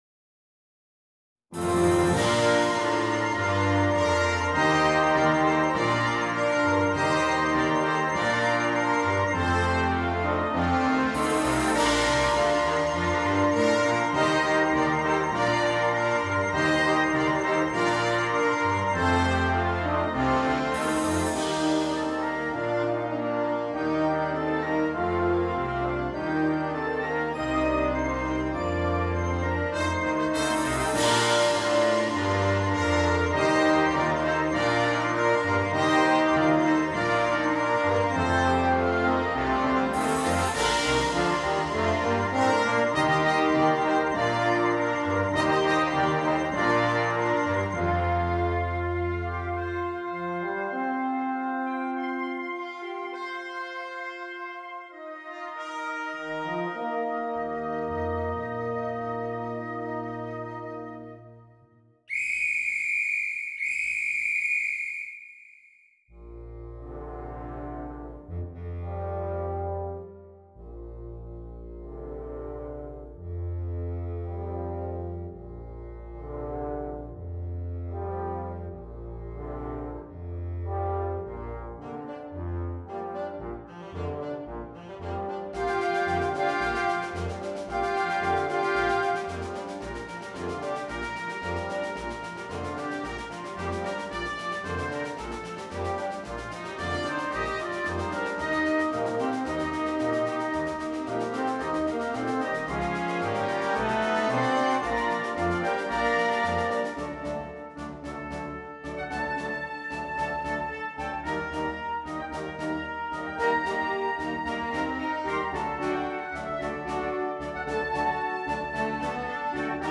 Instrumentation: Symphonic Wind Band